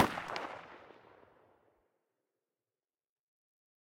svd_veryfar.ogg